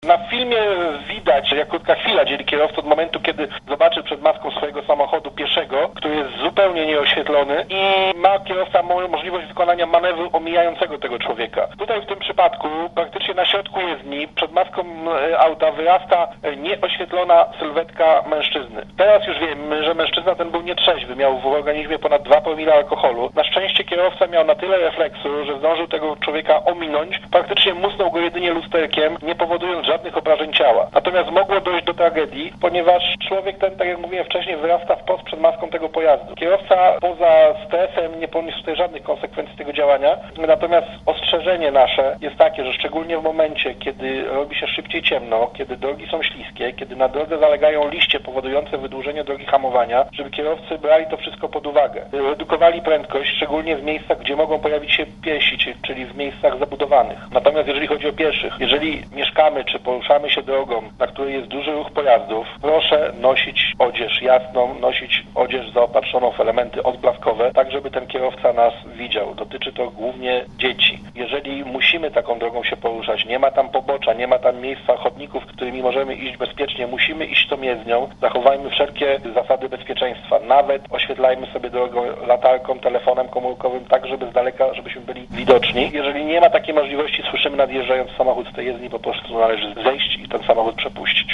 Relacjonuje podinsp.